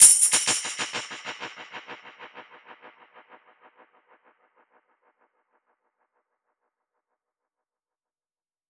DPFX_PercHit_C_95-03.wav